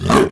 spawners_mobs_uruk_hai_attack.ogg